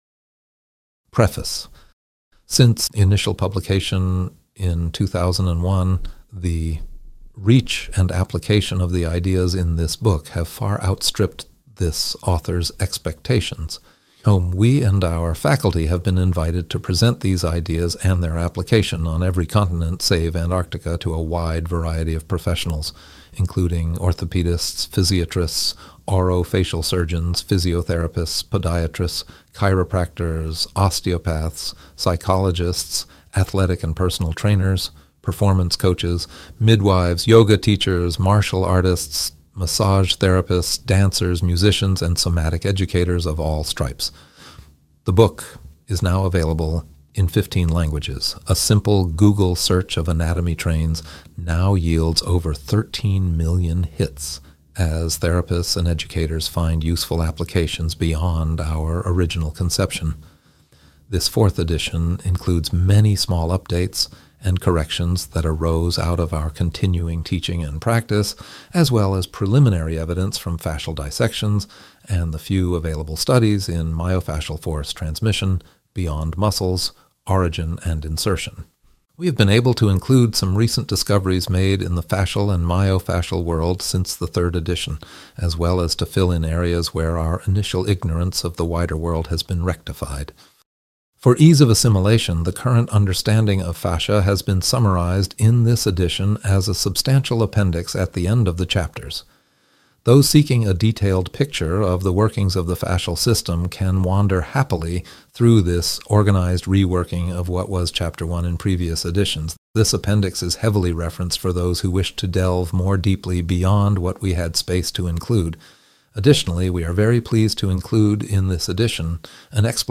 Audiobook – Anatomy Trains 4th Edition